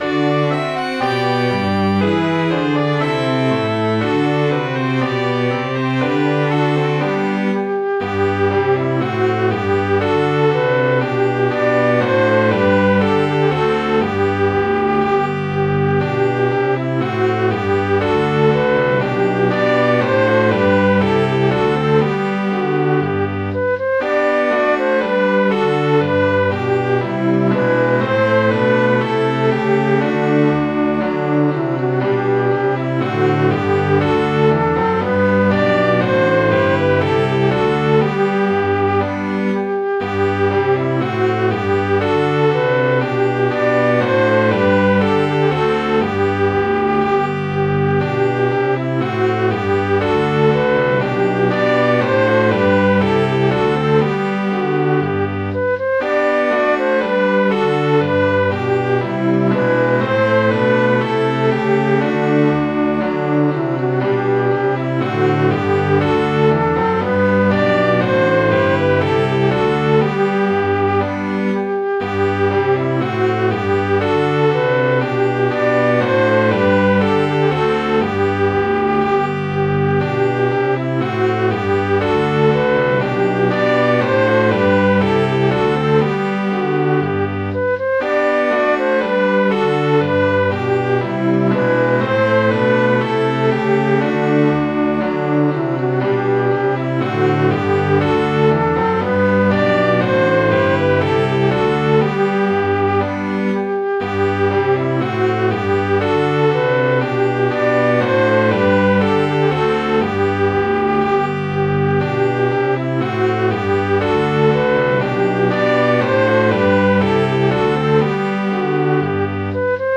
Midi File, Lyrics and Information to The Valiant Lady